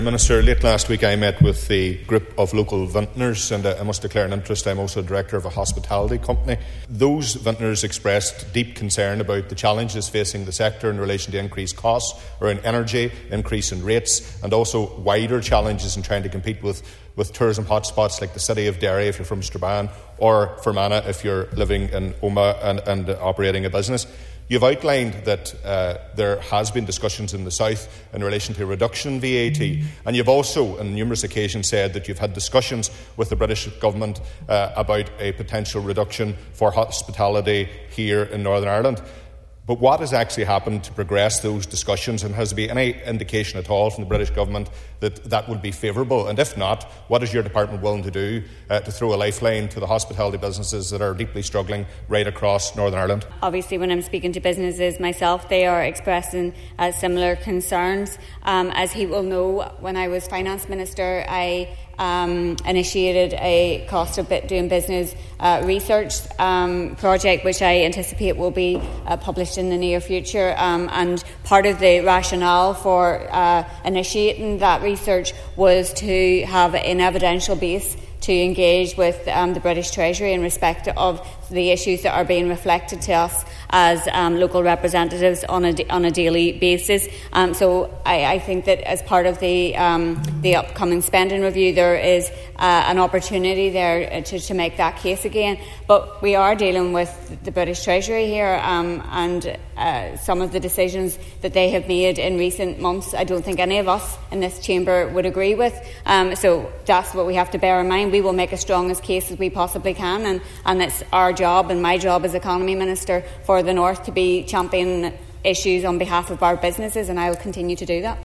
Daniel McCrossan says the issues presented to him at a recent meeting require a quick response………..